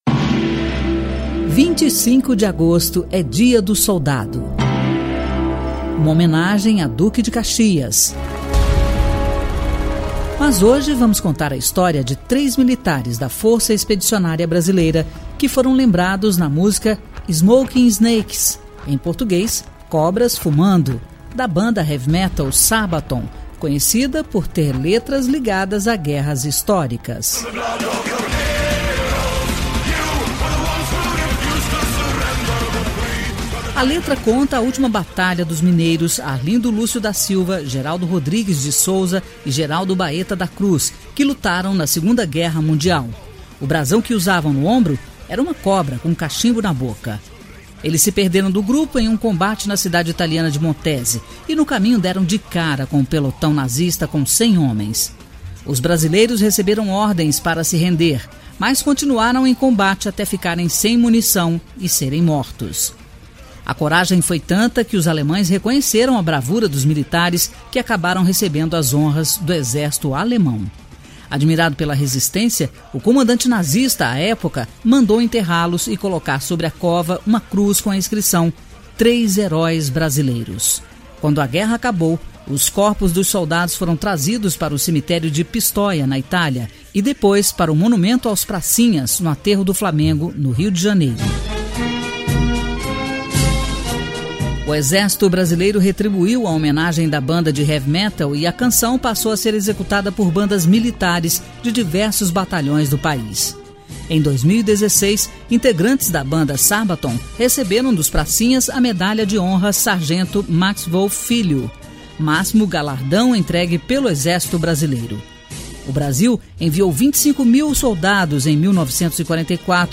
História Hoje: Programete sobre fatos históricos relacionados a cada dia do ano.